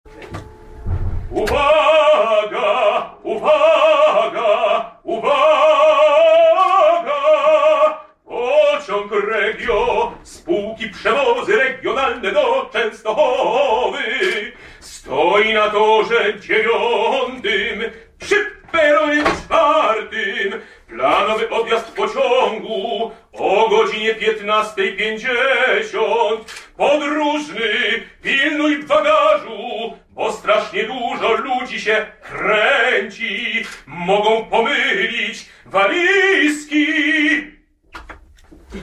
…w dniu dzisiejszym [wczorajszym: 13 X 2011] odbyła się premiera „Opery Dworcowej” Marcina Polaka w przestrzeni Dworca Kolejowego Łódź-Fabryczna.
baryton
dworzec Łódź opera PKP